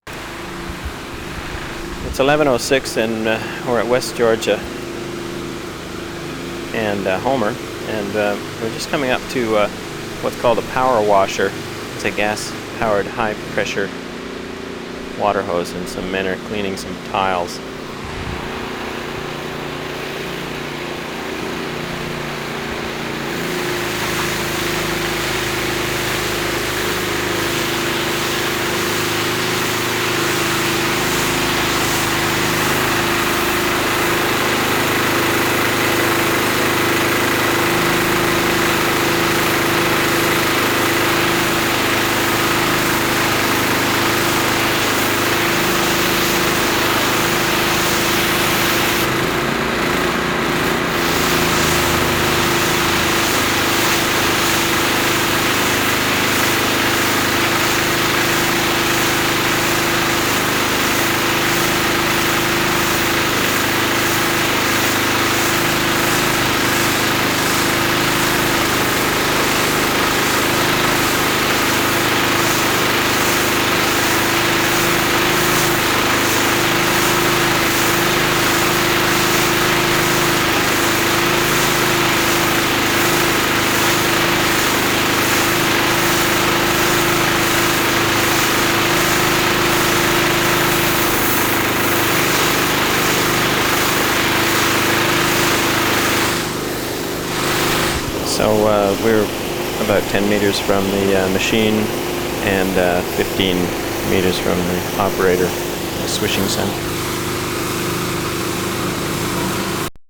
power washer 1:47
15. small gas powered power washer, swish of water on tiles, about 10 meters from machine and operator, ID at 1:37